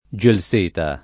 vai all'elenco alfabetico delle voci ingrandisci il carattere 100% rimpicciolisci il carattere stampa invia tramite posta elettronica codividi su Facebook gelseto [ J el S% to ] s. m. — anche gelseta [ J el S% ta ] s. f.